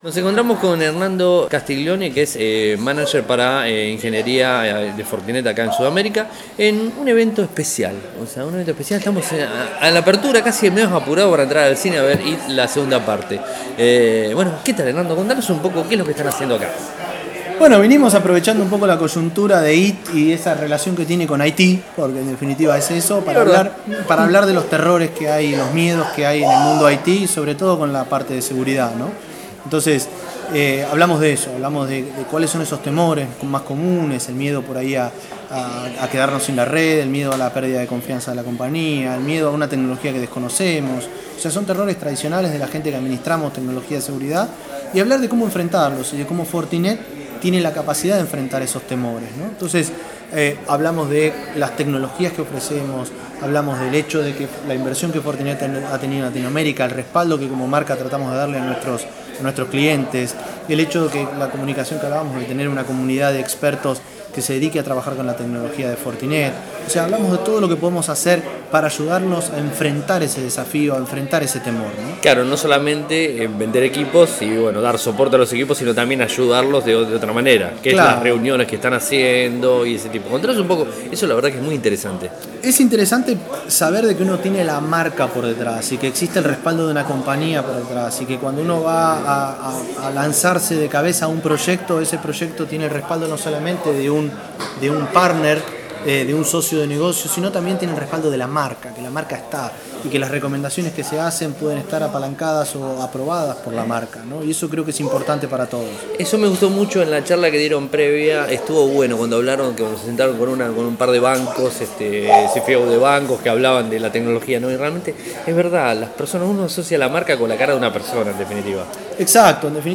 Audio entrevista: